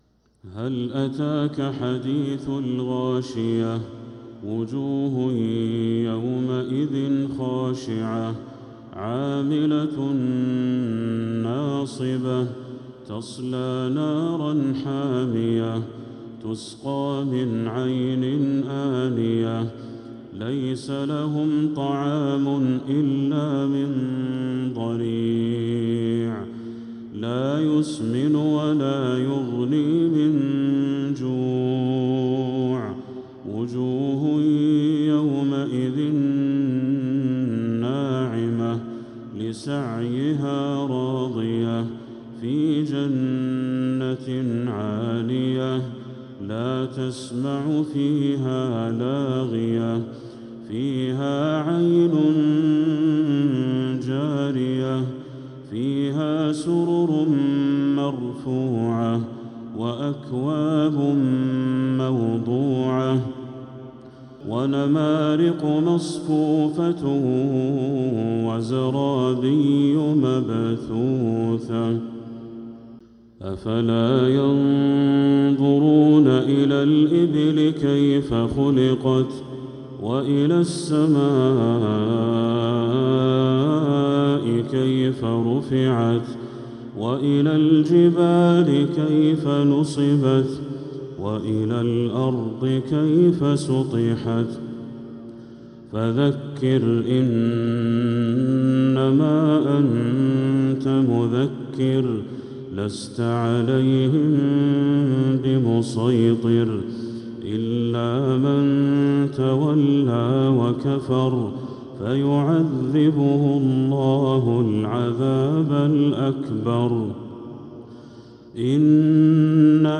من الحرم المكي 🕋